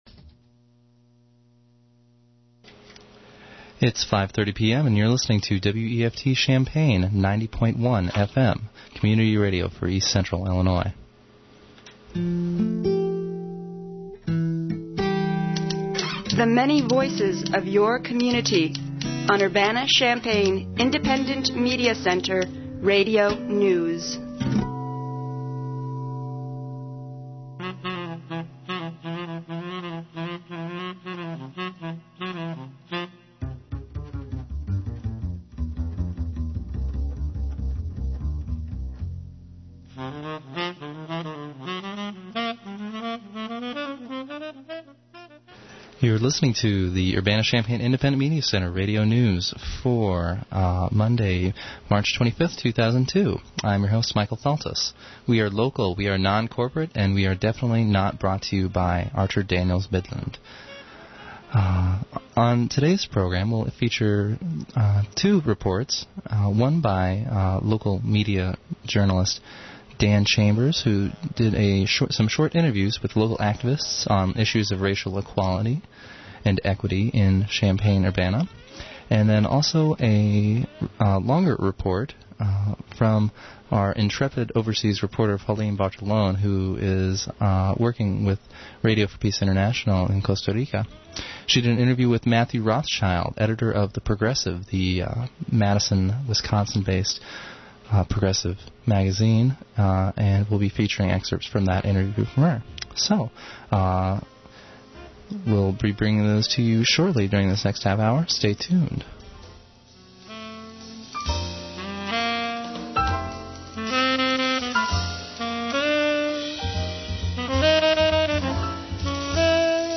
plus local and national headlines